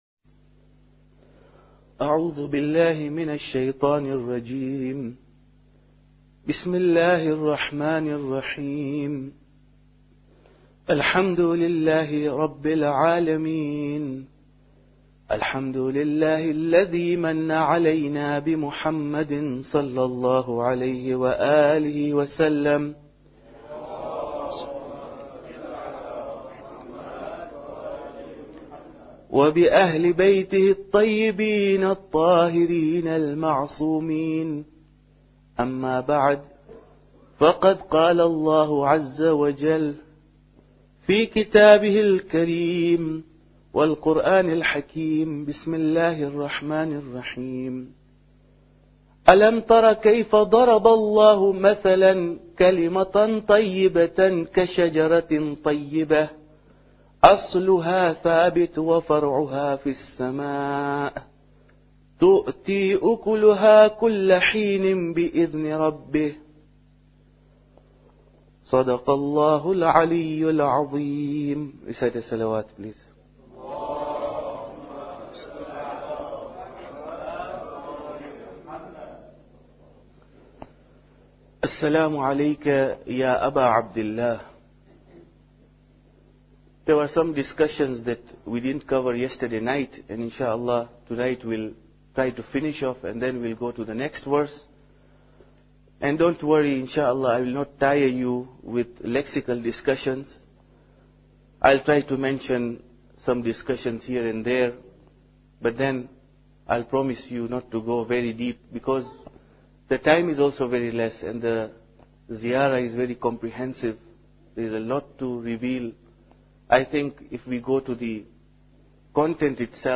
Muharram Lecture 4